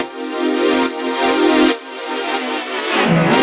Index of /musicradar/french-house-chillout-samples/140bpm/Instruments
FHC_Pad B_140-C.wav